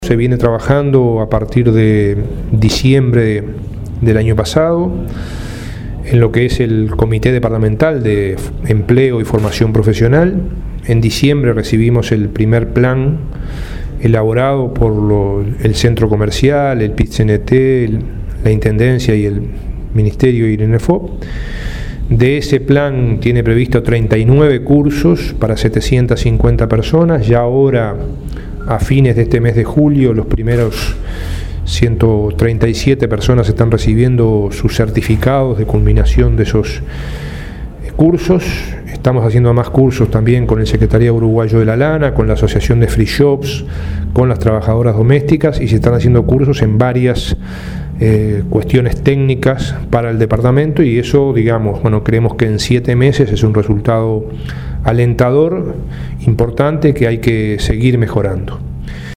El comité departamental de Cerro Largo trabaja en procura de empleo y formación profesional, informó el ministro de Trabajo, Ernesto Murro, previo al Consejo de Ministros abierto en Cerro Largo. En diciembre de 2016 entregó el primer plan de 39 cursos para 750 personas.